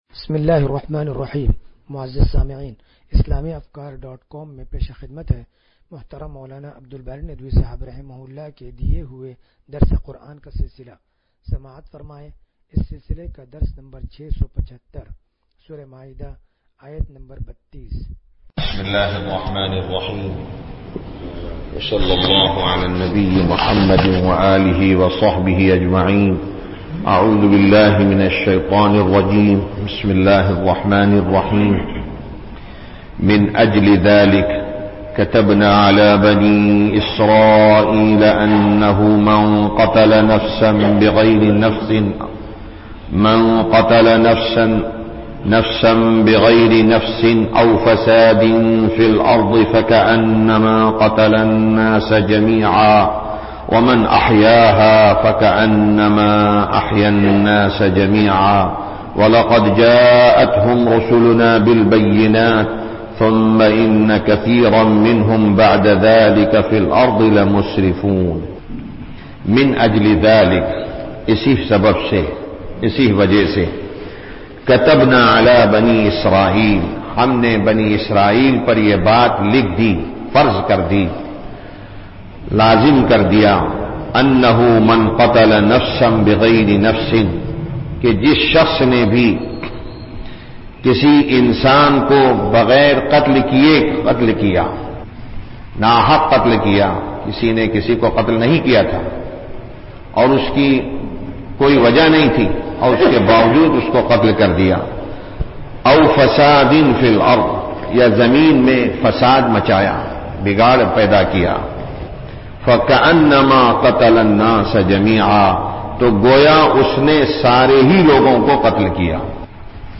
درس قرآن نمبر 0675